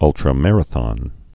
(ŭltrə-mărə-thŏn)